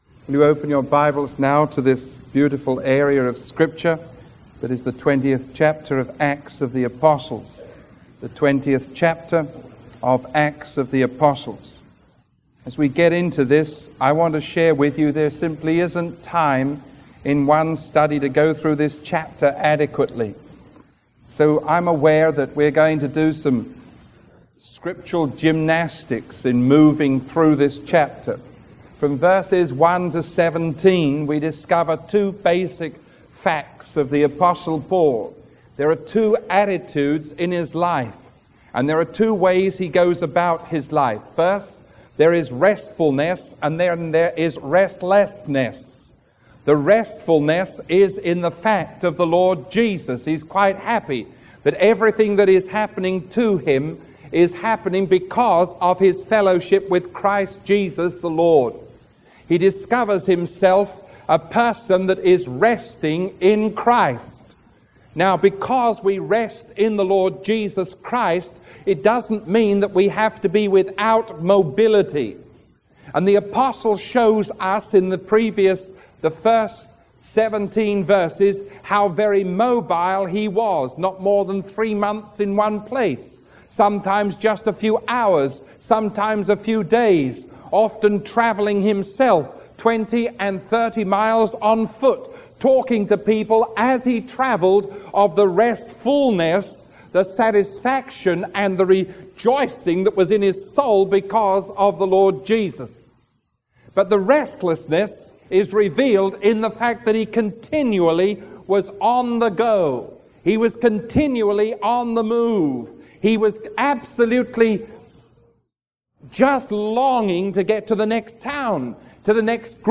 Sermon 0358A recorded on May 31